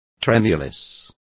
Complete with pronunciation of the translation of tremulous.